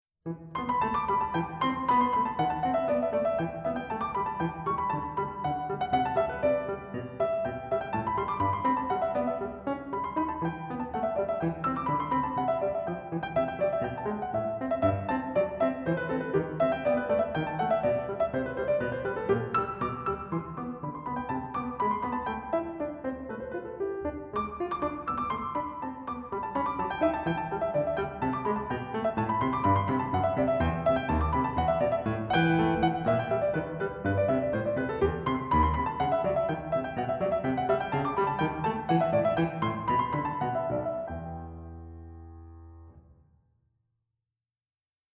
humorous piano pieces
Classical, Keyboard